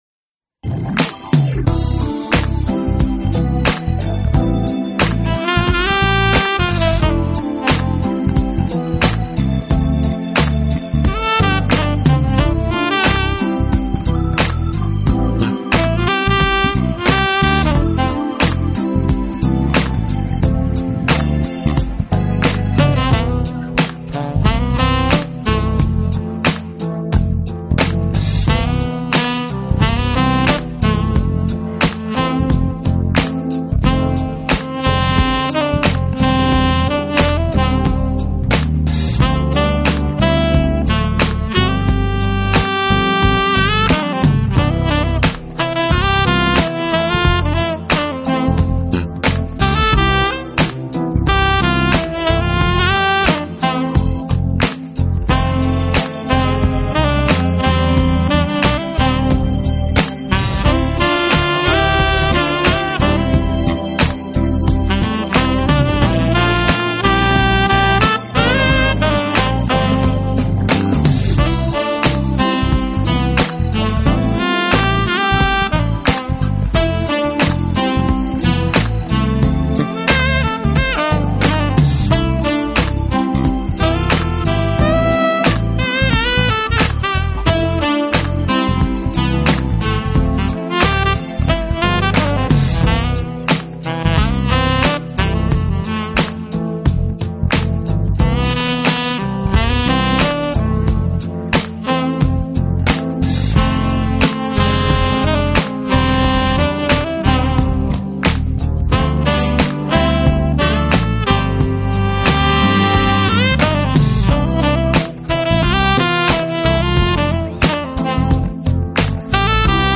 音乐类型: Smooth Jazz
的音乐充满动感，节奏轻快